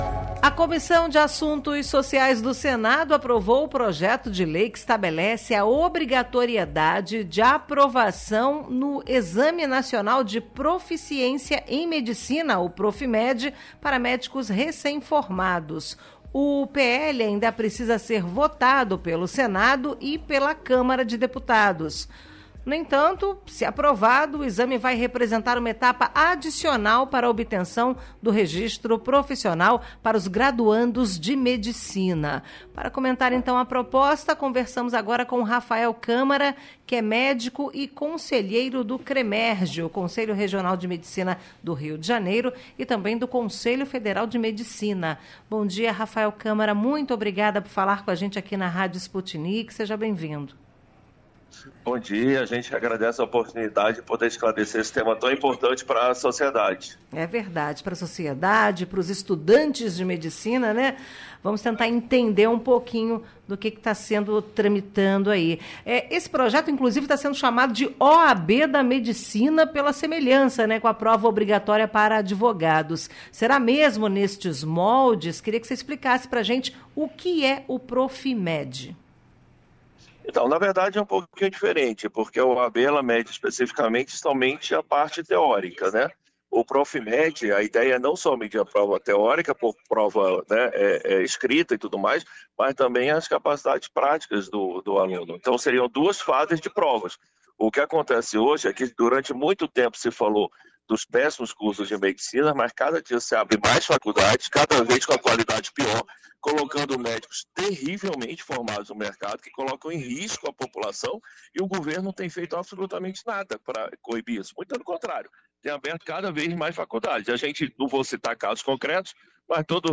O conselheiro federal e do CREMERJ, Raphael Câmara, foi entrevistado sobre o assunto e falou sobre a importância da avaliação para a medicina no país.